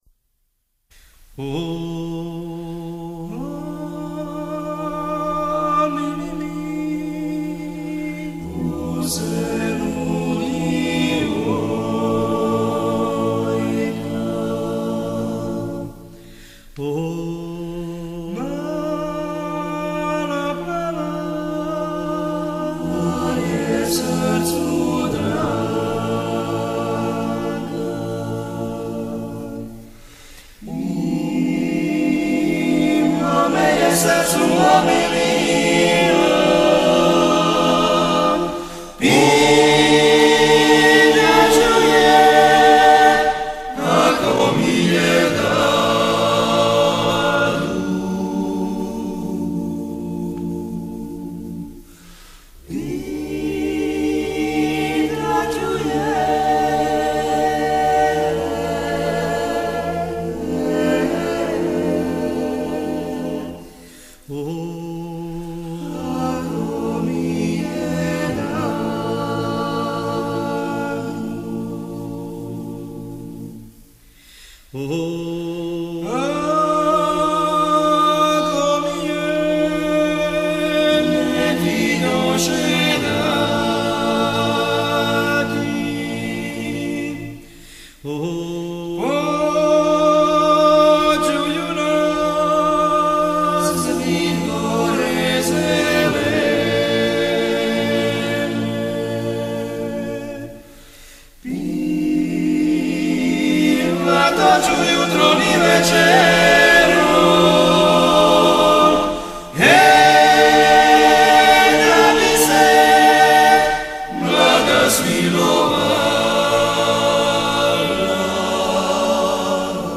I. Tenor
Bariton
I. Bas